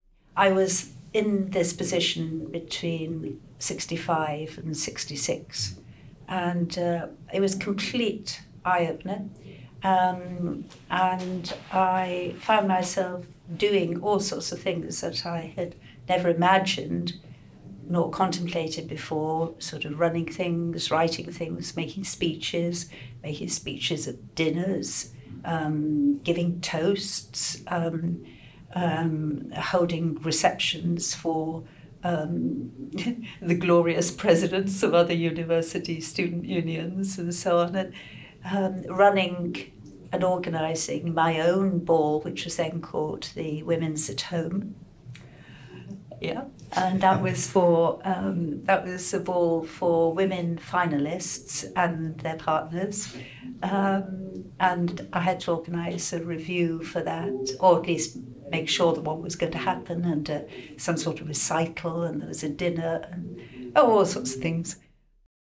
Excerpt taken from an oral history interview for the Generation UCL project